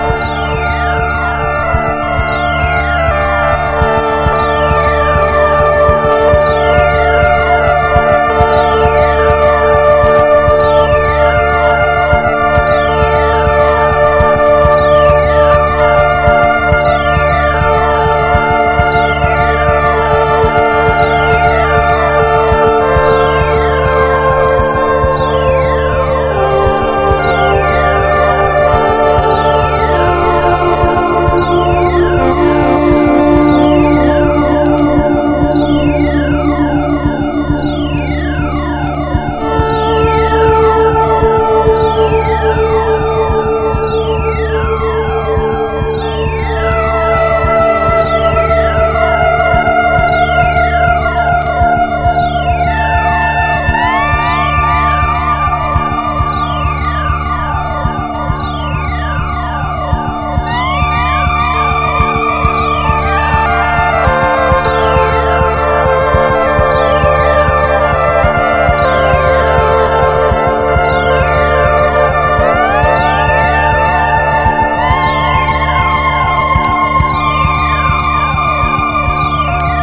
DISCO / FUNK / SYNTHESIZER / JAPANESE DISCO
和ライト・メロウ・ブラス・ファンク定番～和シンセ・コズミック・ディスコまで収録の非売品最強スプリット盤！